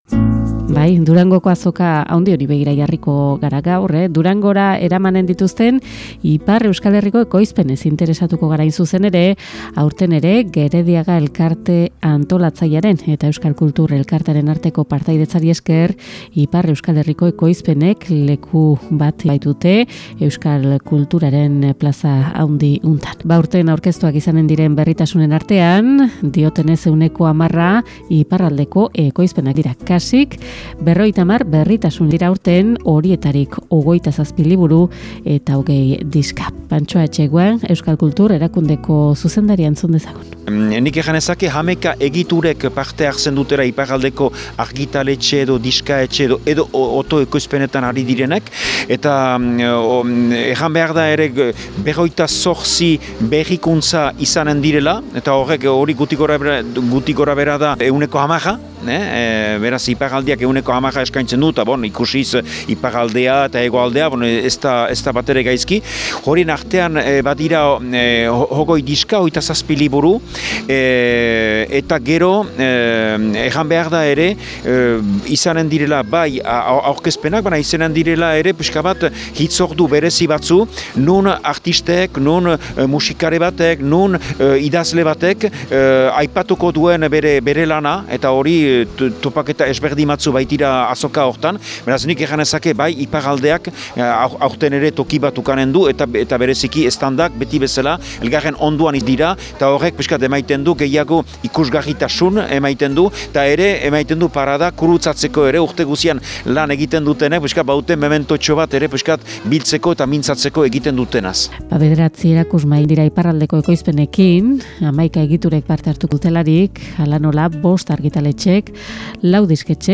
TERTULIA